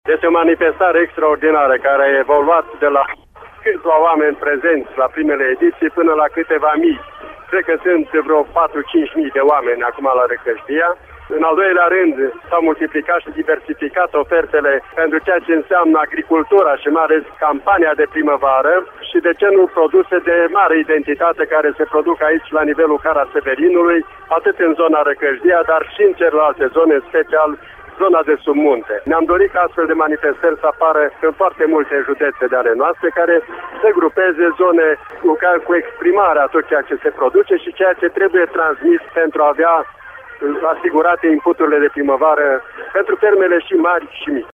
Preşedintele Academiei de Știinţe Agricole și Silvice, Valeriu Tabără, fost ministru al Agriculturii, a constat că oferta este tot mai diversificată.